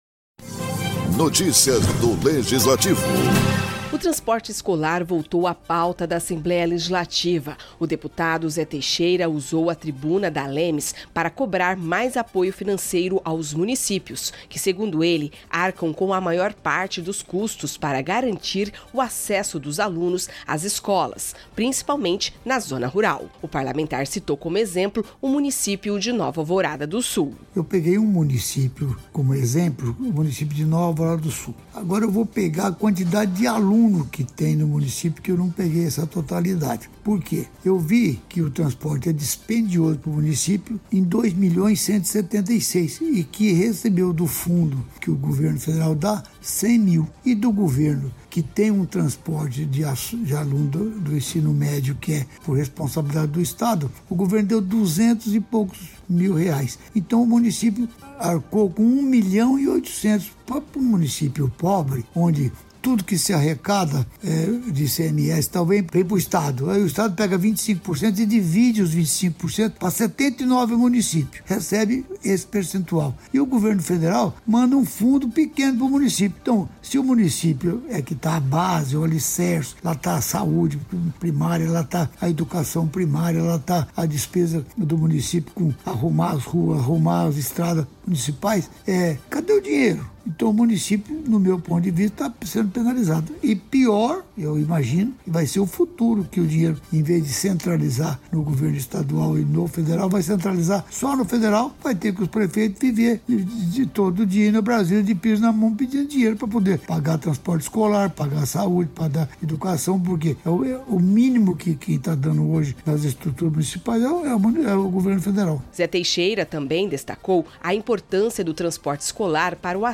O deputado Zé Teixeira alertou, durante sessão ordinária, para sobrecarga dos municípios, especialmente na zona rural, e defende divisão mais equilibrada dos custos entre os entes federativos.